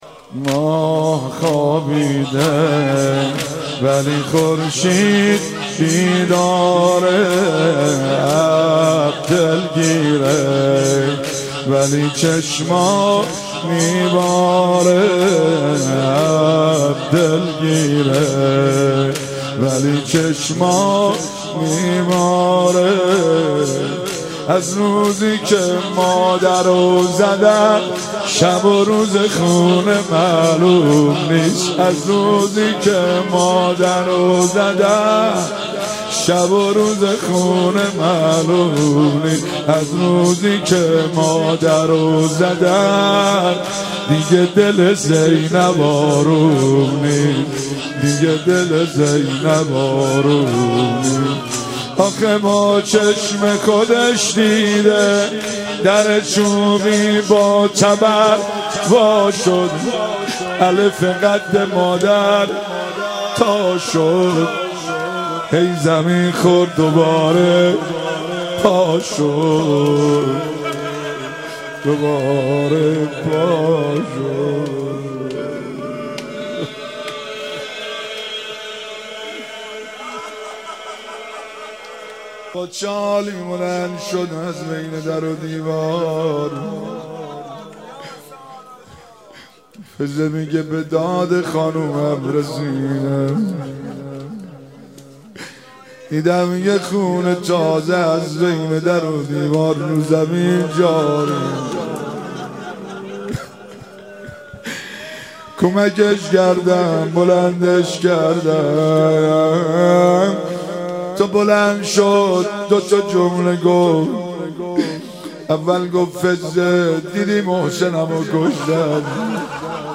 27 بهمن 95 - زمینه - ماه خوابیده ولی خورشید بیداره